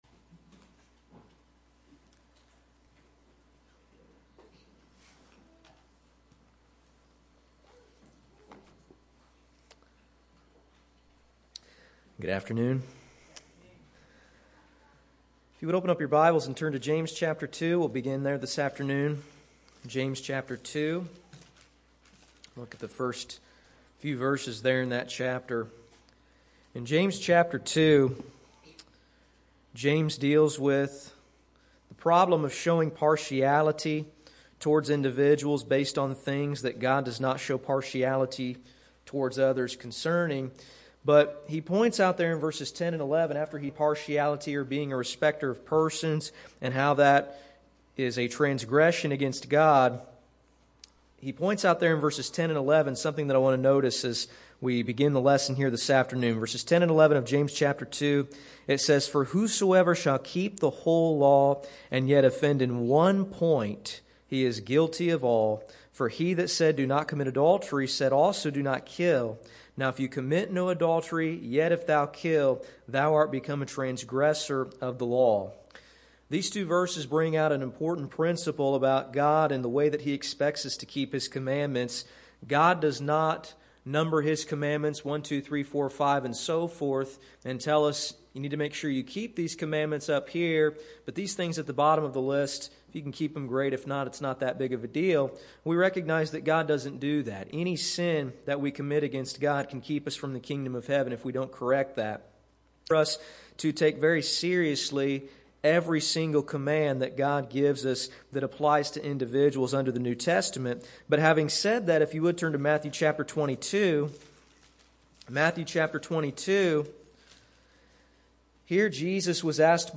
Sermons - Olney Church of Christ
Service: Sunday PM